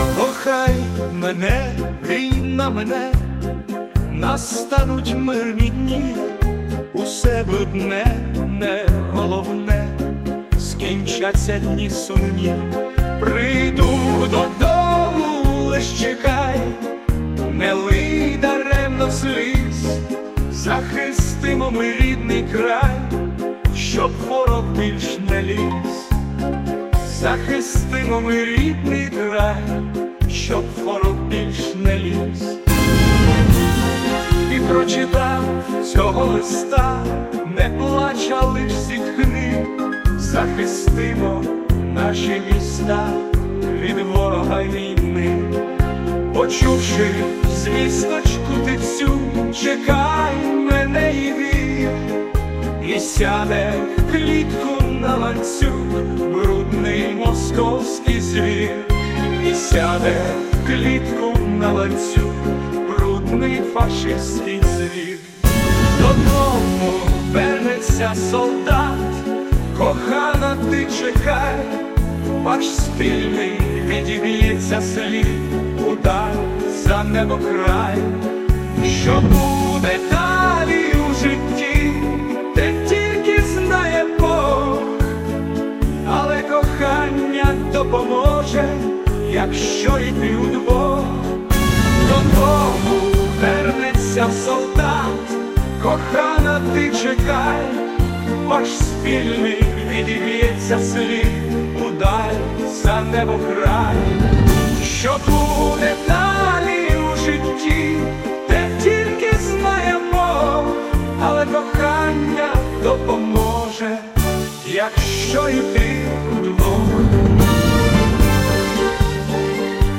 ТИП: Пісня
СТИЛЬОВІ ЖАНРИ: Ліричний